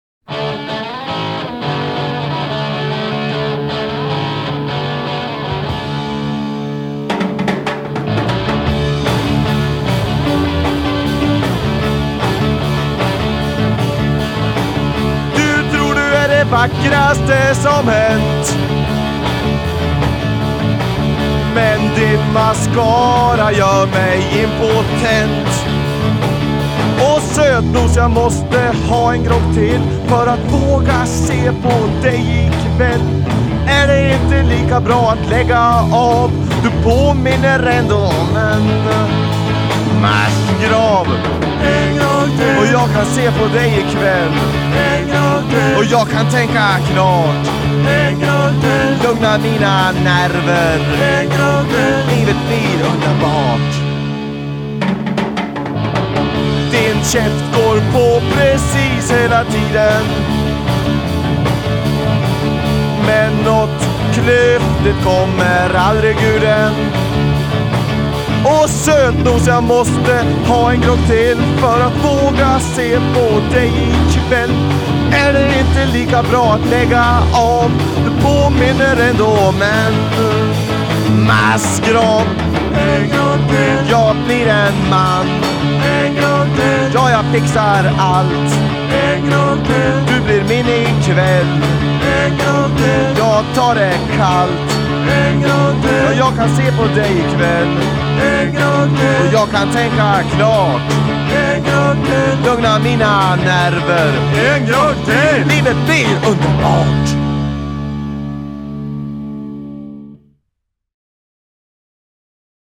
bas och sång
trummor
gitarr
Inspelad på Saga i Strömsund i augusti 1979.
Sångpålägg och mixning i källarstudion